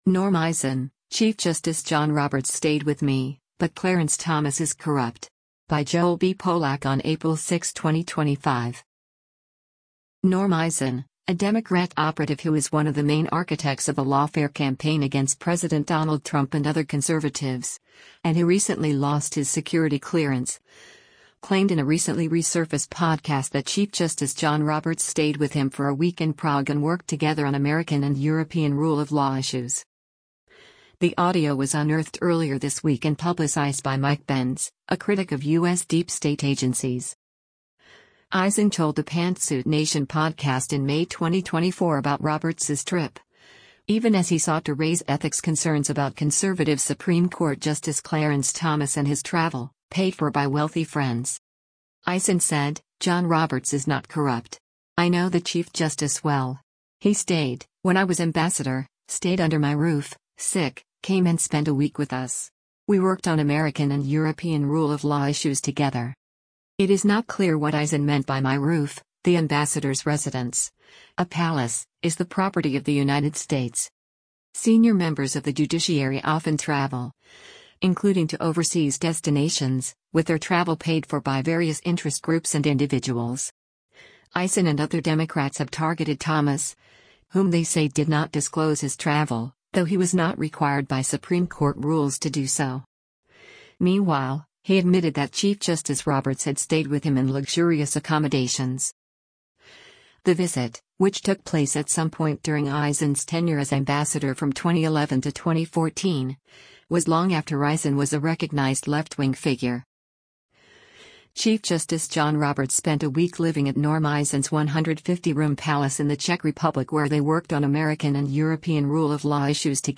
Eisen told the Pantsuit Nation podcast in May 2024 about Roberts’s trip — even as he sought to raise “ethics” concerns about conservative Supreme Court Justice Clarence Thomas and his travel, paid for by wealthy friends.